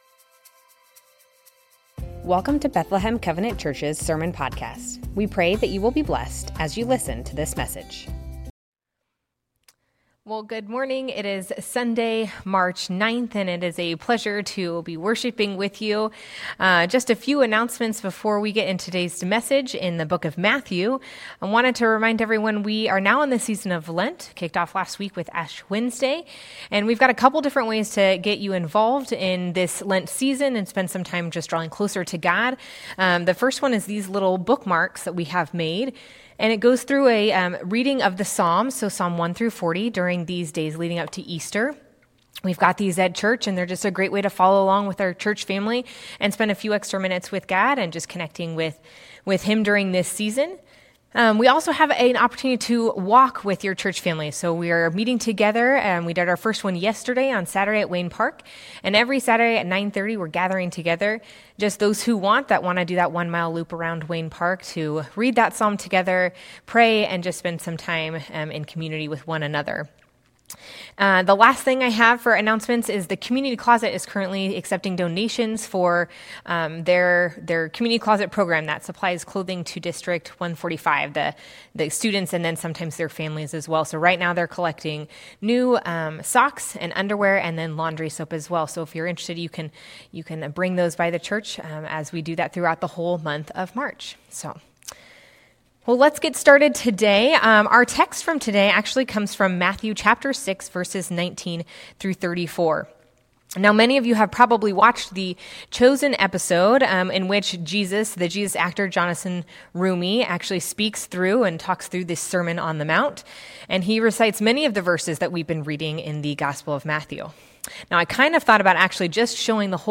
Bethlehem Covenant Church Sermons Matthew 6:19-34 - Wealth & Worry Mar 09 2025 | 00:25:16 1x 00:00 / 00:25:16 Subscribe Share Spotify RSS Feed Share Link Embed